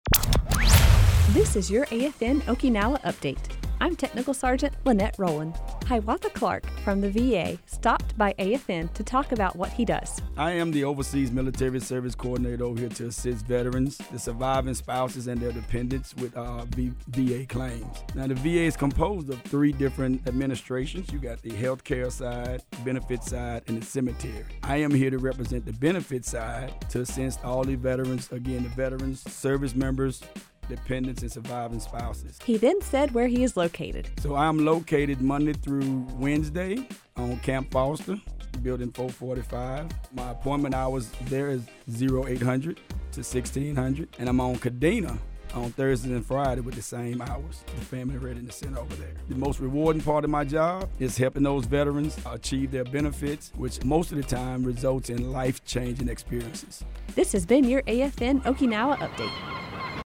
VA overseas military representative newscast